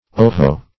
oho - definition of oho - synonyms, pronunciation, spelling from Free Dictionary Search Result for " oho" : The Collaborative International Dictionary of English v.0.48: Oho \O*ho"\, interj. An exclamation of surprise, etc. [1913 Webster]
oho.mp3